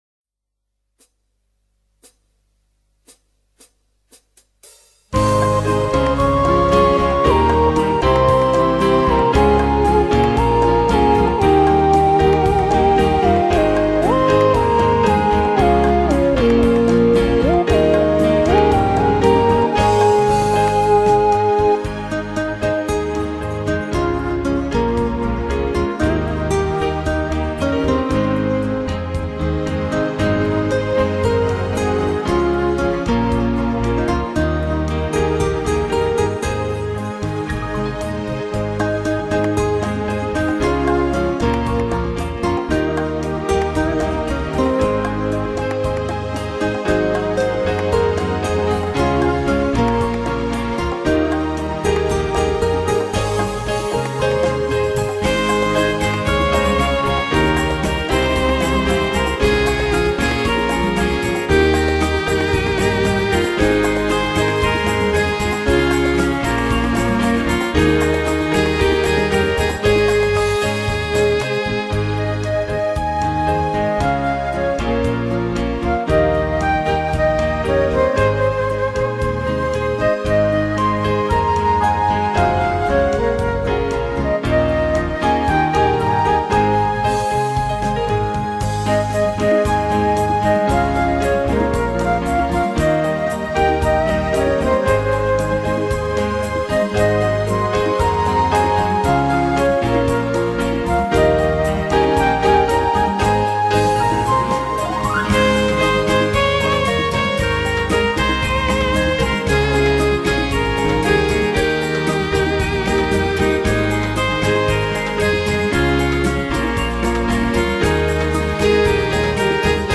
ترانيم الميلاد